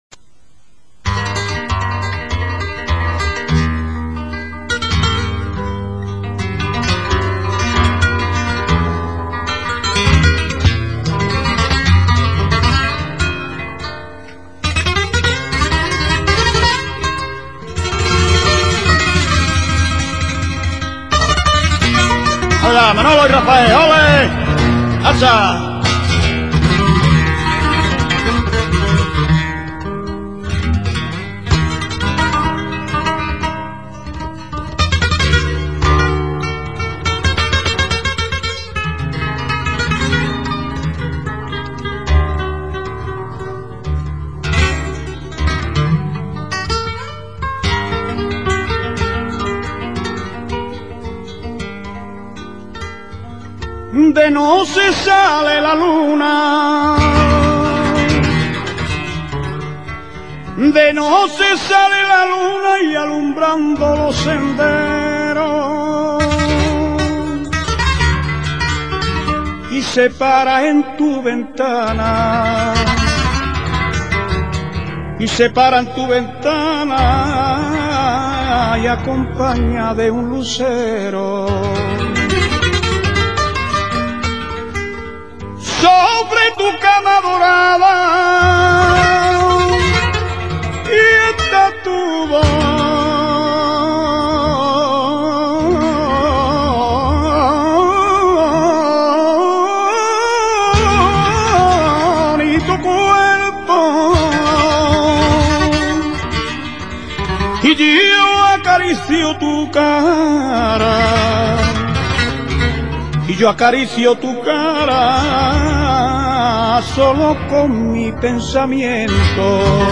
Sonidos y Palos del Flamenco
Es un cante de car�cter triste, lento, con letras generalmente de temas amorosos, que hablan de penas y desenga�os.
vidalita.mp3